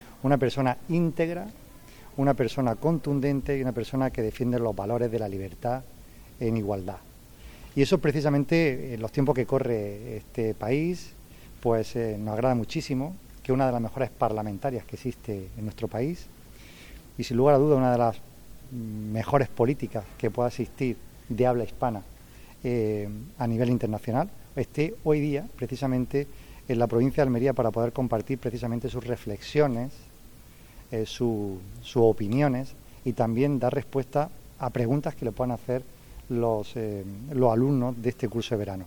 La diputada nacional, periodista y doctora en Historia Moderna por la Universidad de Oxford, Cayetana Álvarez de Toledo, ha pronunciado la conferencia de clausura del curso de verano de la Universidad de Almería: ‘Política, sociedad y comunicación’.
La diputada nacional, periodista y doctora en Historia ha protagonizado la última conferencia de este curso, celebrado en el MUREC, que ha tratado sobre la refundación del orden liberal
10-07_cursos_ual__com.__soc.__y_politica__cayetana__presidente_diputacion.mp3